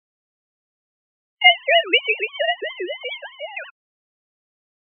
Mintamondatok a kétféle szintetizált beszédingerre:
Szinuszhullámú beszéd